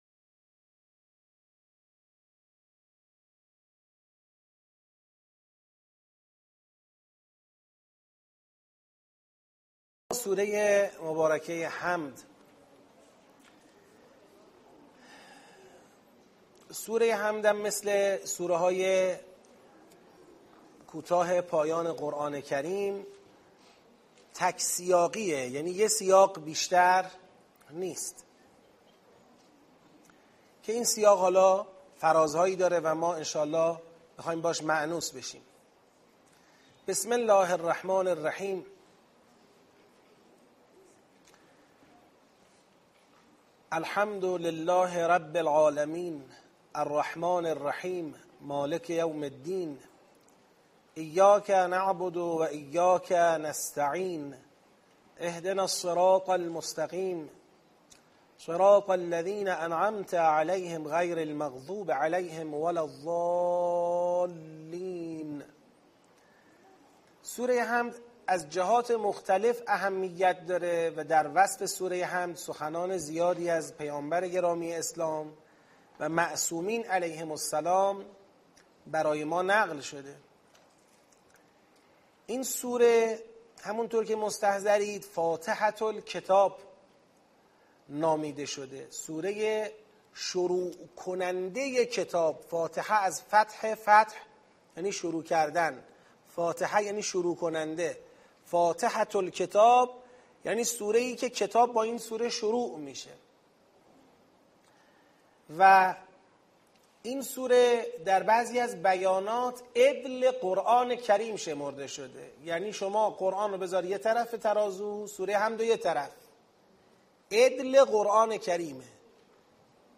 به حول و قوه الهی دوره آموزش معرفتی تدبر در قرآن تابستان ۹۸ با حضور ۲۵۰ نفر از قرآن آموزان در مسجد پیامبر اعظم (ص) شهرک شهید محلاتی تهران آغاز شد.